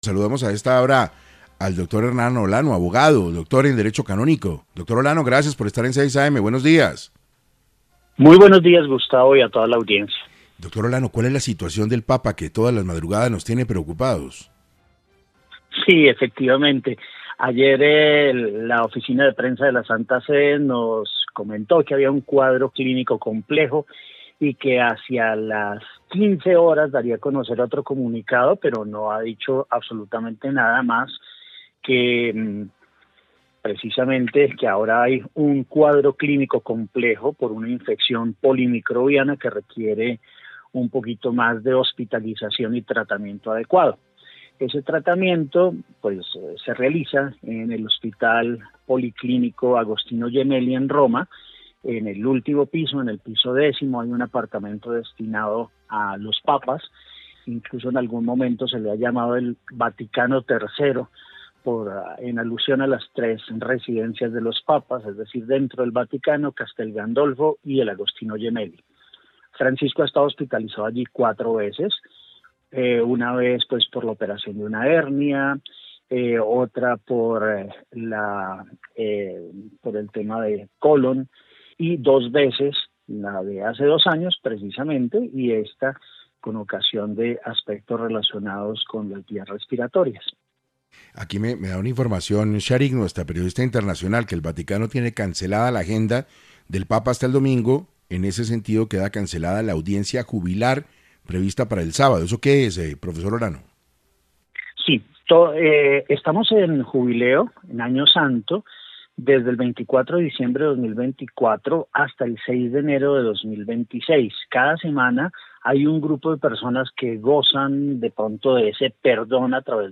abogado explicó en #6AM lo que podría venir para la iglesia católica con la infección polimicrobiana que padece el sumo pontífice.